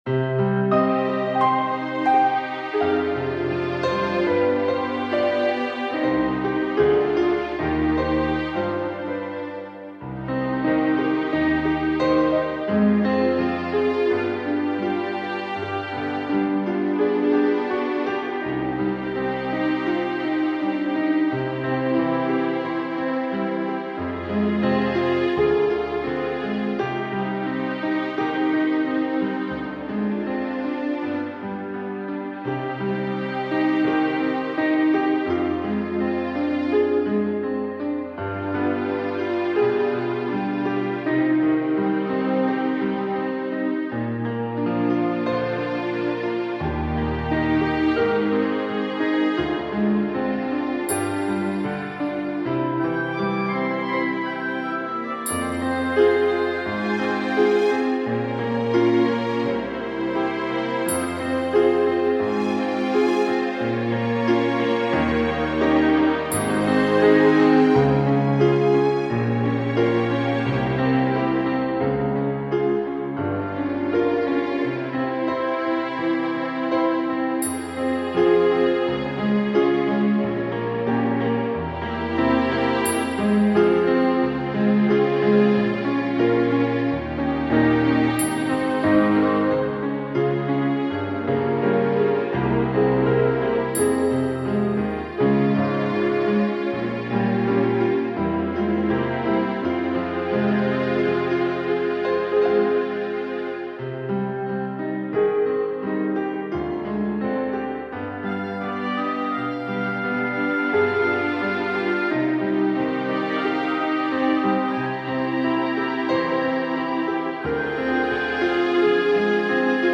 детской песни